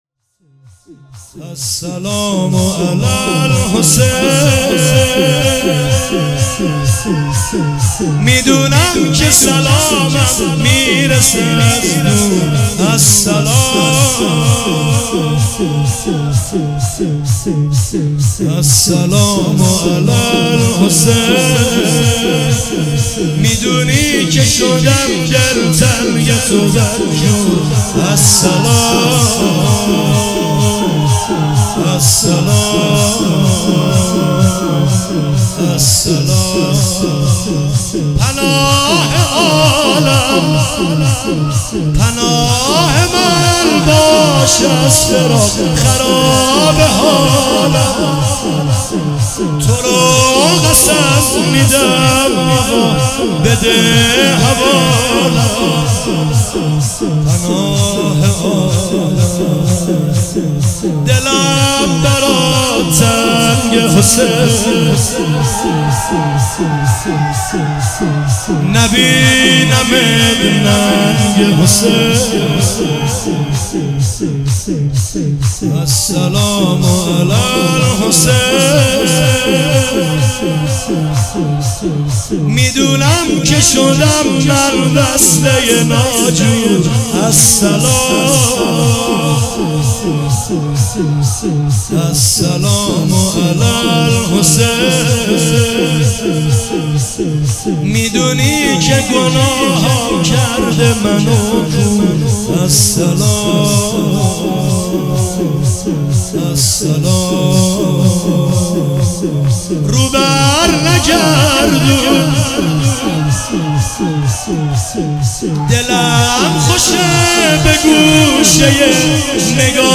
تک نوحه